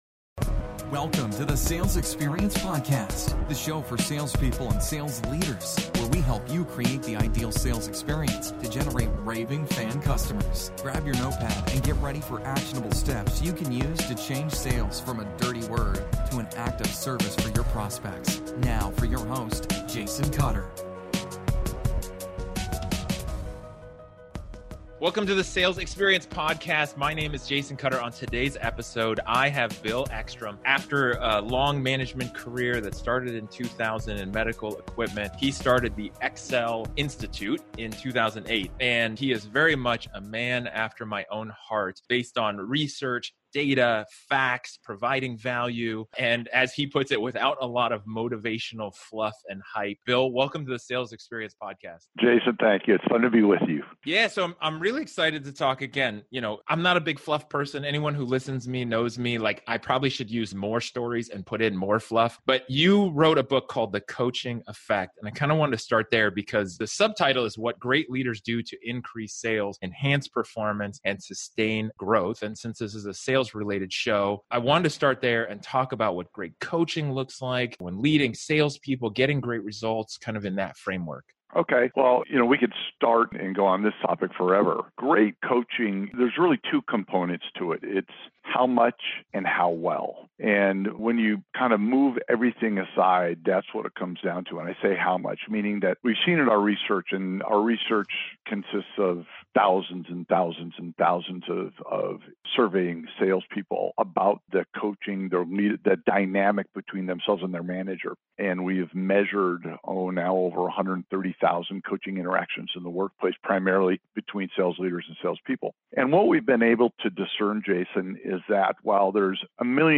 If you are a sales leader – are you coaching or managing your team? If you are a sales professional – do you want to be coached and held accountable? On this next 4-part series I have a great conversation